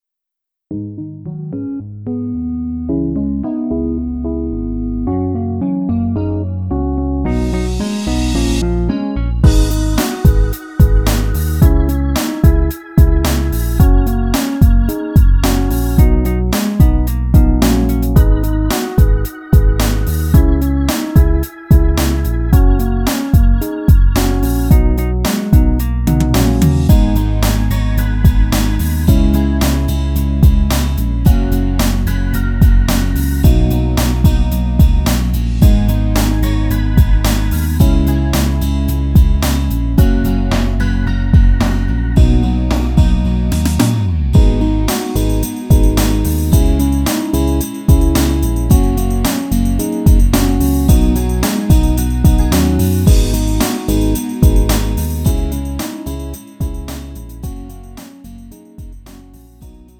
음정 -1키 3:07
장르 가요 구분